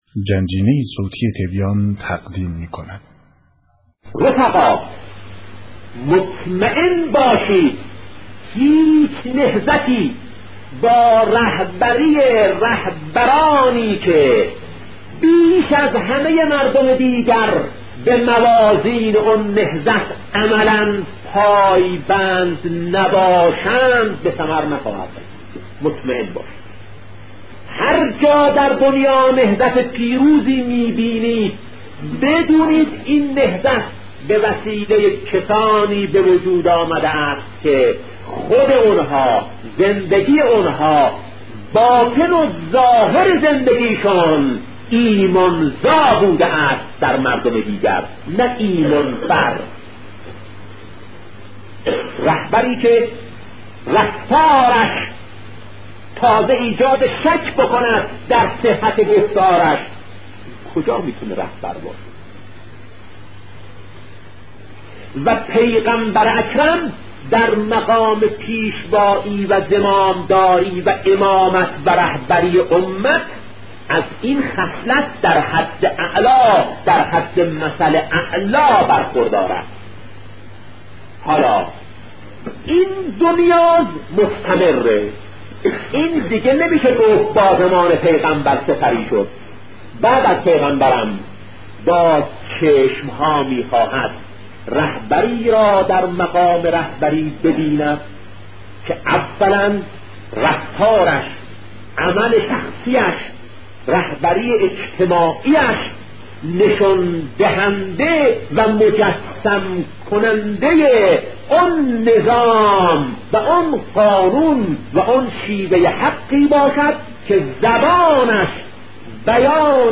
حاکمیت و امامت، با صوت دلنشین شهید بهشتی(ره)- بخش‌دوم